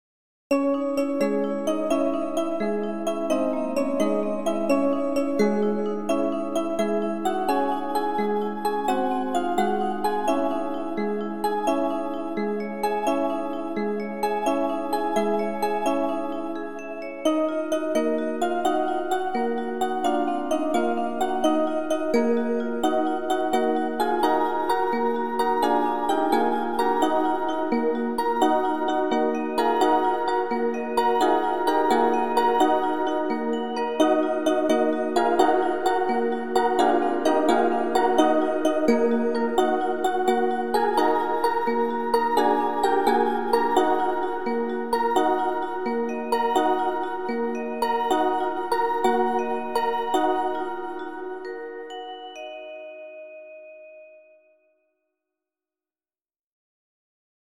folk song Switzerland